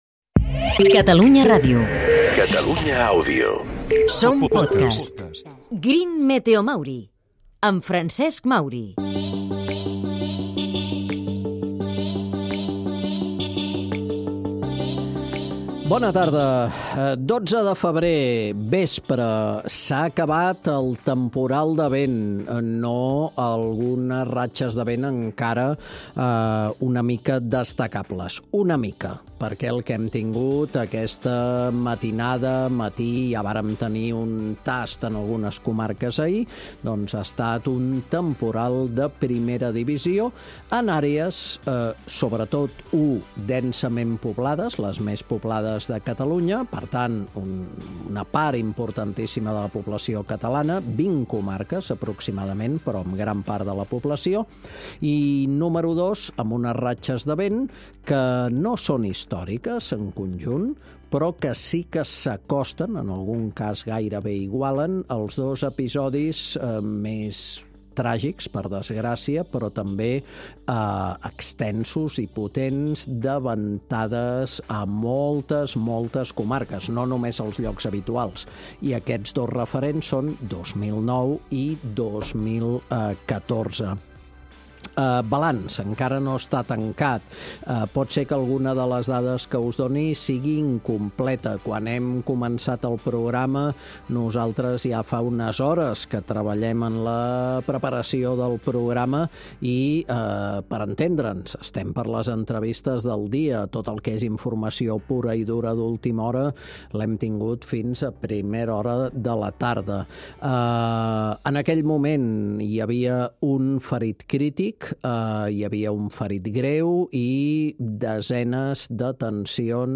"GreenMeteoMauri" que posa la lupa en les Terres de l'Ebre i en les diverses i potents oportunitats que hi arriben amb l'economia verda. Entre altres temes, el suport als divulgadors ambientals davant dels atacs negacionistes completa un programa amb música de sostenibilitat.